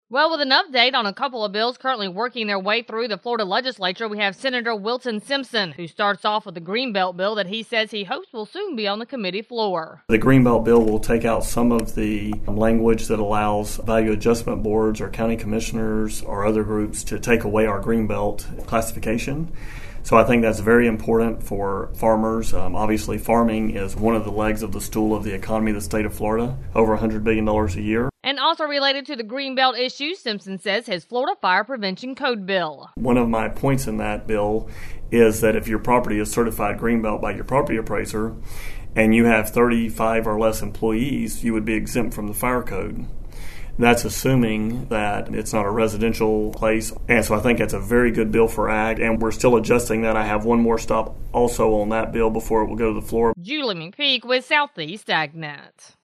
In the following report, we have Senator Wilton Simpson with an update on a couple of bills currently working their way through the Florida Legislature.